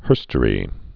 (hûrstə-rē)